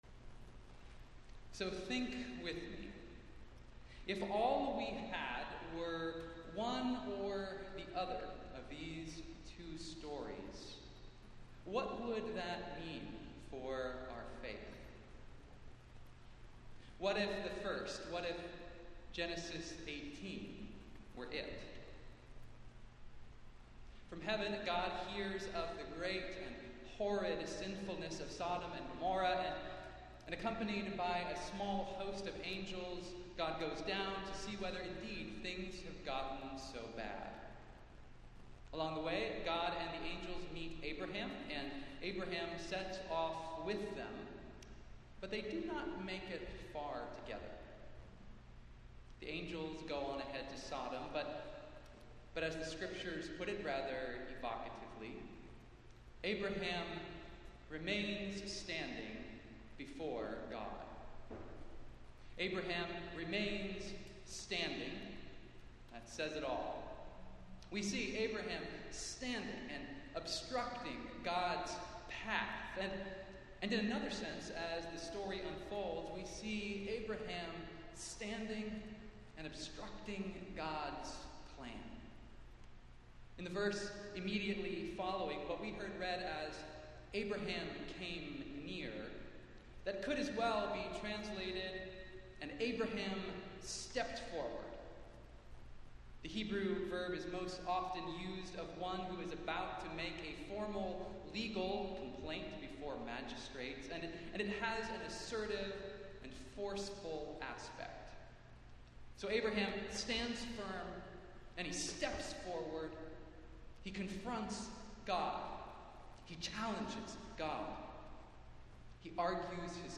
Festival Worship - Fourteenth Sunday after Pentecost